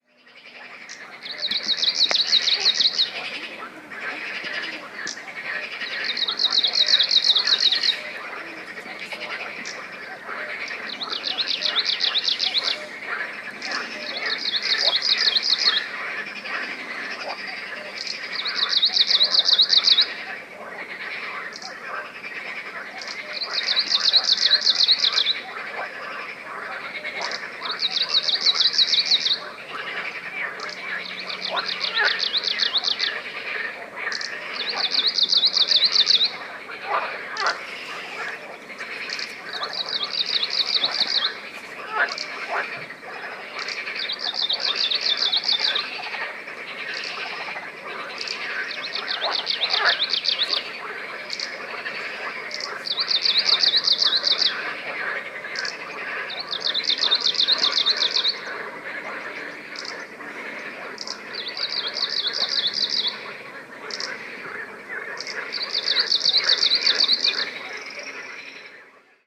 Regarding my final recording for this post, it may seem odd to hear Kamchatka Leaf Warbler amid a frog concert, but this is possible in Kamchatka, where introduced frogs have survived for decades in lakes, even through the harsh winter, through thermal hot water support!
This recording shows nicely the rich variety of strophes displayed in the song. It also contains the clicking calls that you can hear on migration.